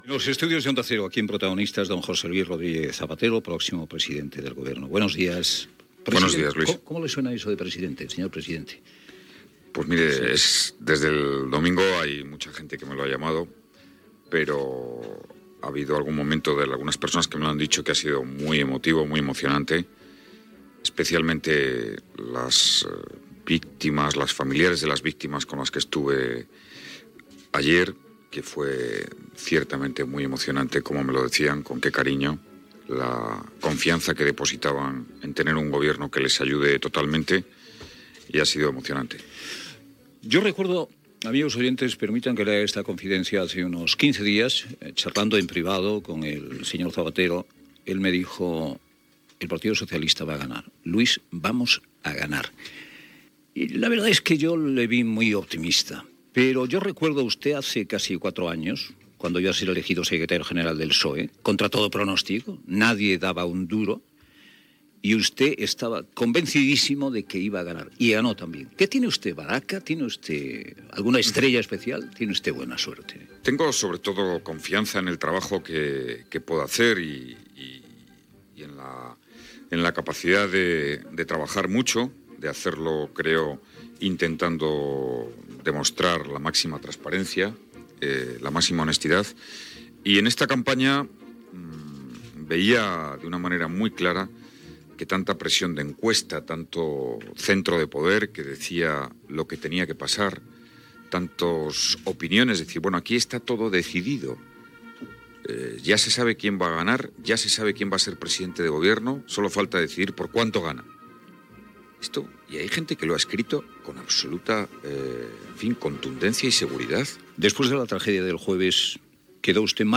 Fragment d'una entrevista al president del govern espanyol José Luis Rodríguez Zapatero.
Info-entreteniment